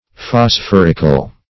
Phosphorical \Phos*phor"ic*al\, a.